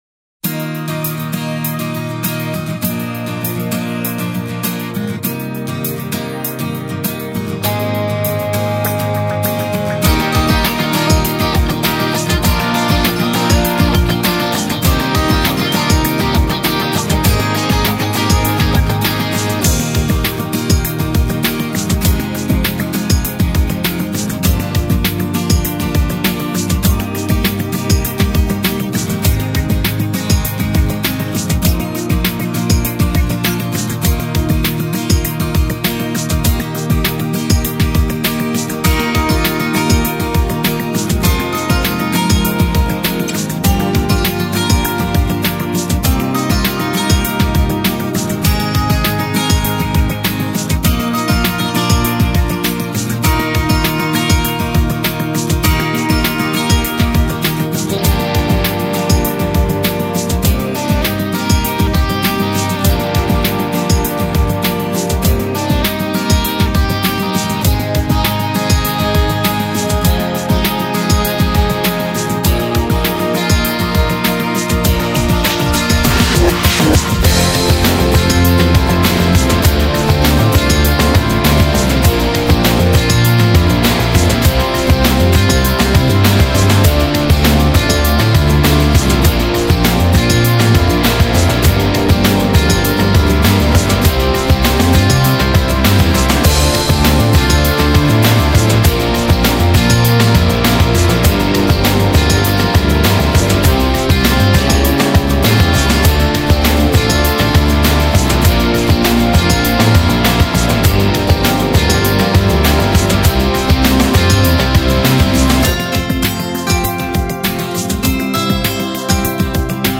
La dulce melodía que habla de un amor especial